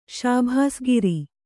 ♪ śabhāsgiri